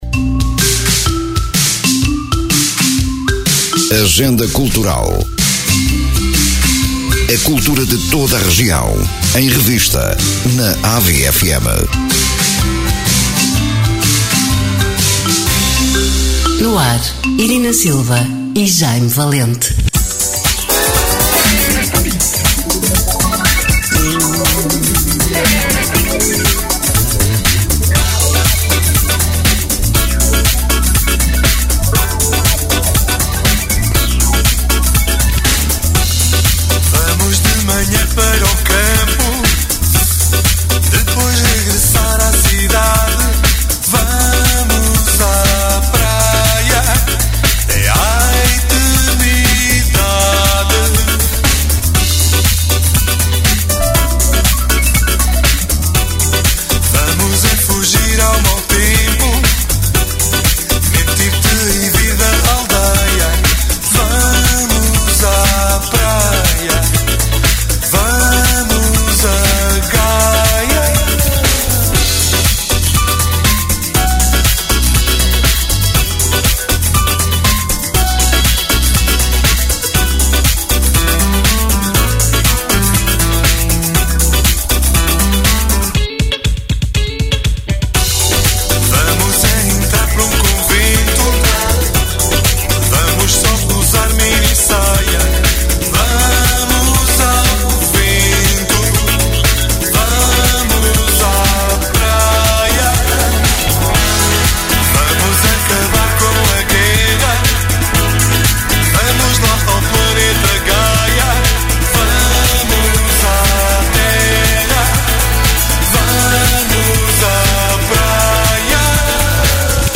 Emissão: 13 de Fevereiro 2024 Descrição: Programa que apresenta uma visão da agenda cultural de Ovar e dos Concelhos vizinhos: Estarreja, Feira, Espinho, Oliveira de Azeméis, São João da Madeira, Albergaria-a-Velha, Aveiro e Ílhavo. Programa com conteúdos preparados para ilustrar os eventos a divulgar, com bandas sonoras devidamente enquadradas.